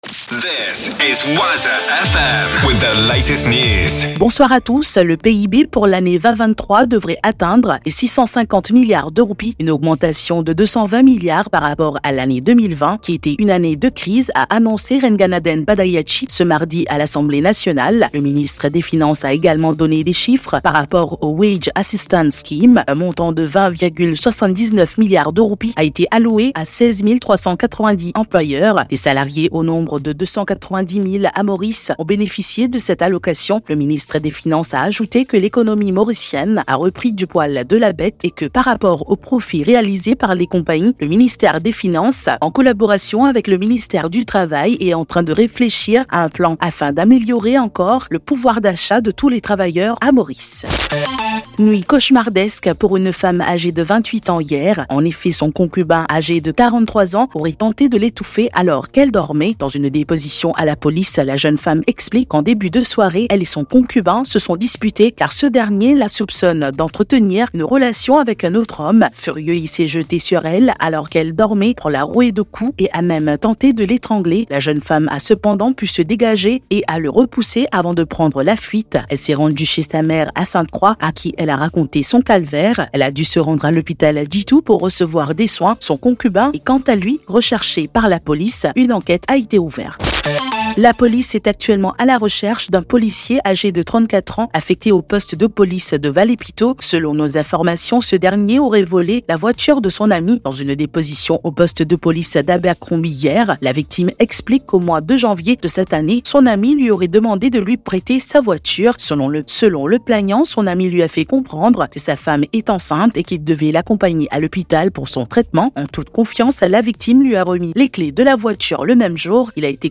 NEWS 20H - 8.11.23